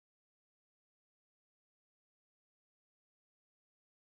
4 seconds of silence in a 8k mono WAV file
silence-4_seconds-8k-mono.wav